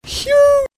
beacon
Category: Animals/Nature   Right: Personal